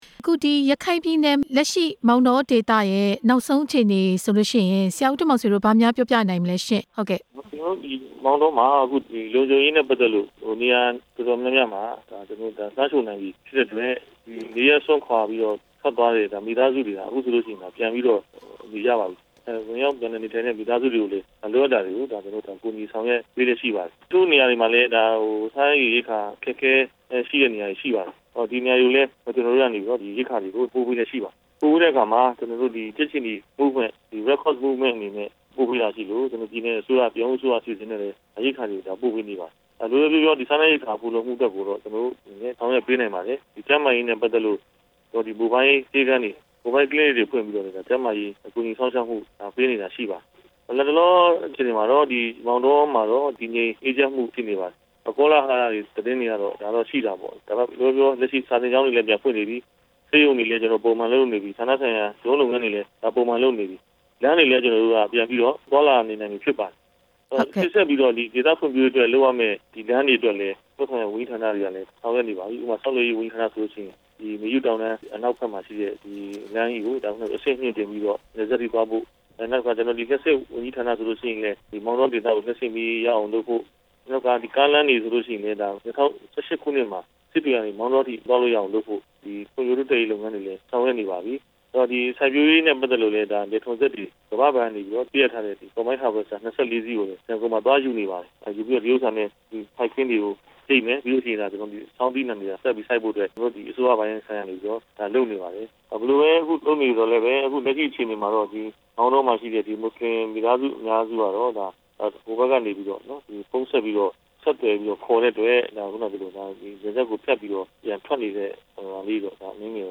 မောင်တော အခြေအနေ မေးမြန်းချက်